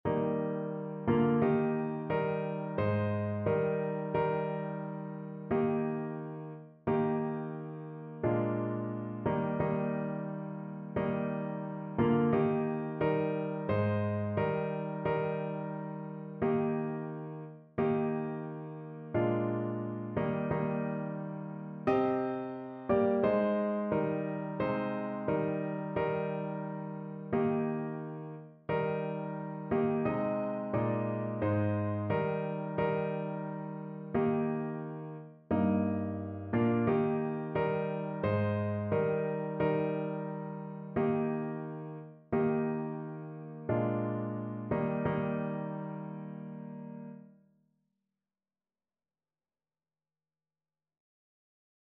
Notensatz 1 (4 Stimmen gemischt)
• gemischter Chor [MP3] 811 KB Download